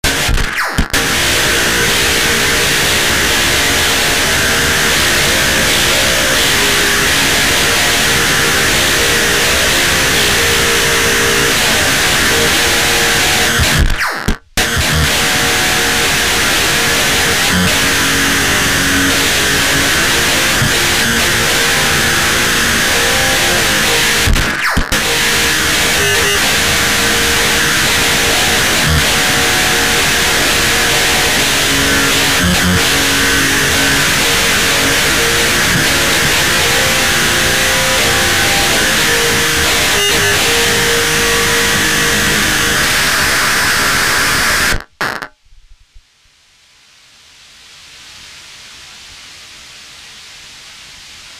Má opravdu originální, jedinečný a neuchopitelný zvuk. Zdá se nám až asstrálně vyvážený.
Soubory ke stažení Kanál CRY (822.67 kB) Kanál SPATTER (822.67 kB)